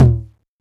cch_perc_tom_mid_banga.wav